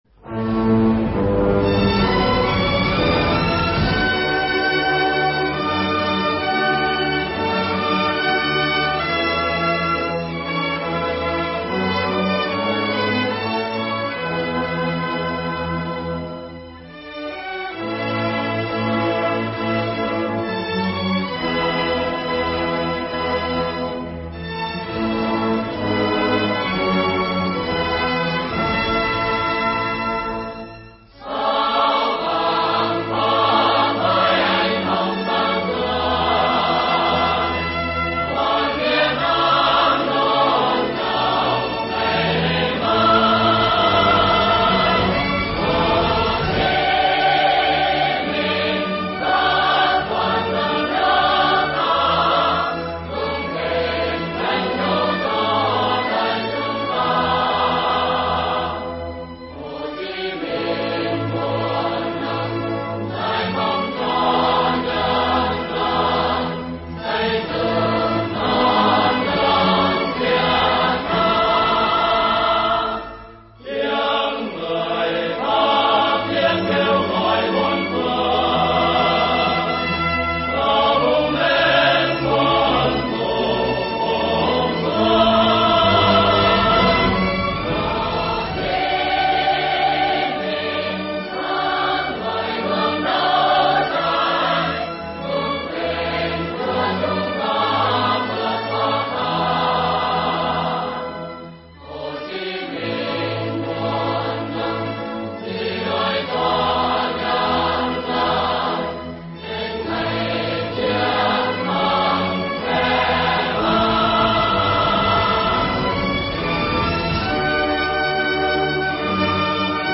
File nhạc có lời